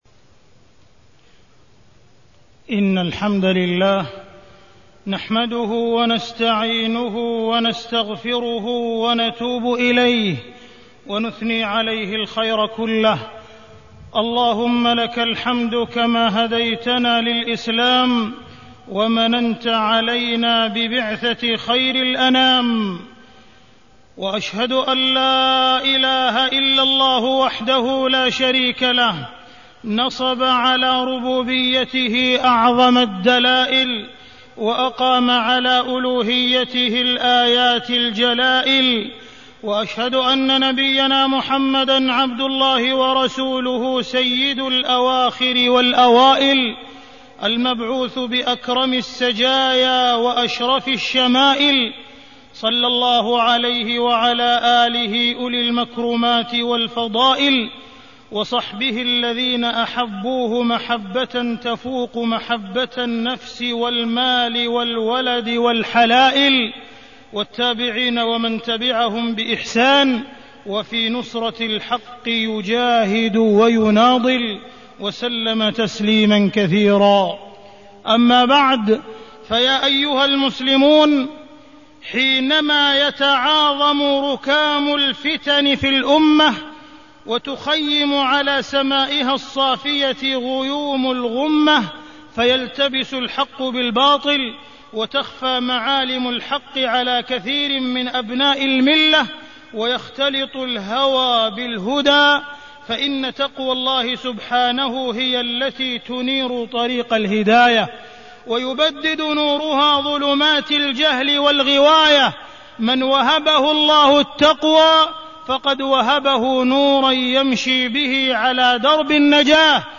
تاريخ النشر ١٩ ربيع الأول ١٤٢٣ هـ المكان: المسجد الحرام الشيخ: معالي الشيخ أ.د. عبدالرحمن بن عبدالعزيز السديس معالي الشيخ أ.د. عبدالرحمن بن عبدالعزيز السديس الشمائل المحمدية